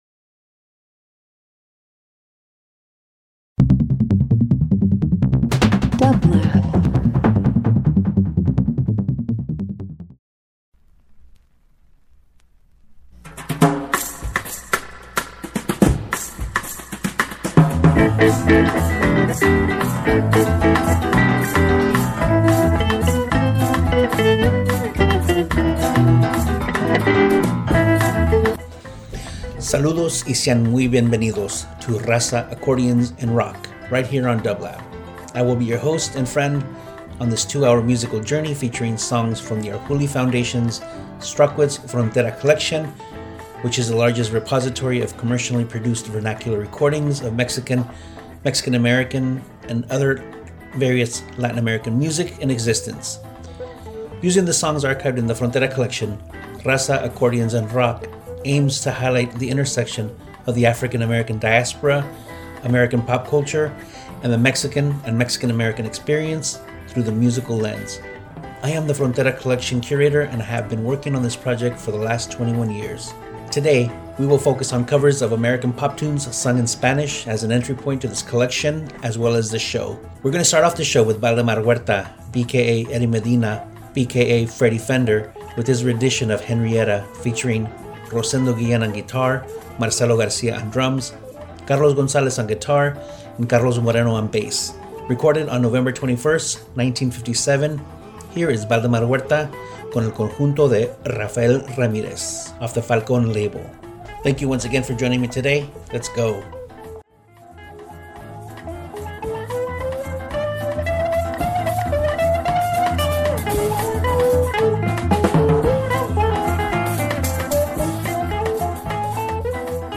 Latin R&B Rock Soul